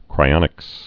(krī-ŏnĭks)